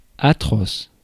Ääntäminen
IPA : /ˈsævɪdʒ/